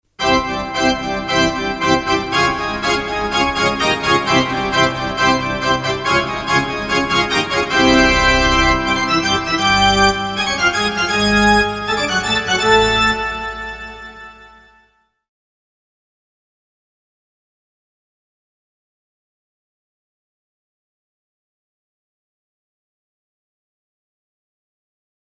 organnaya-hokkeynaya-muzyka-3muzofon.com_.mp3